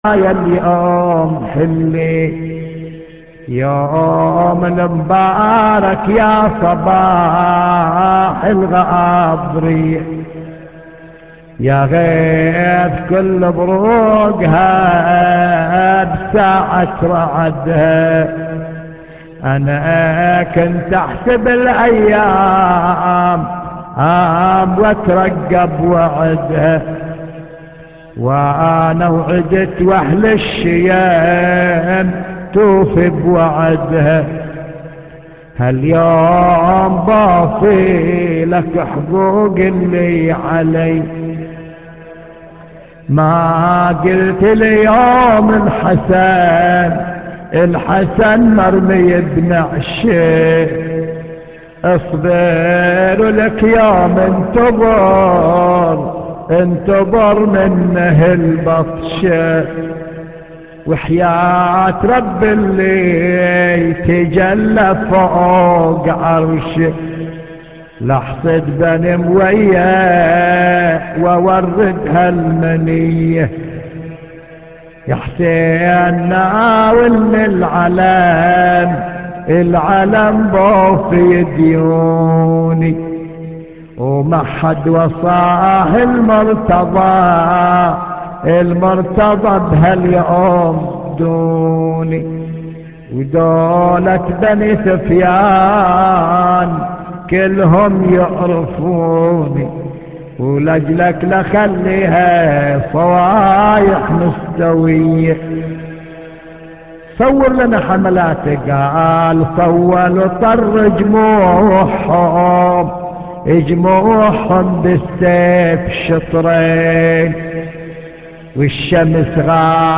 نواعـــــي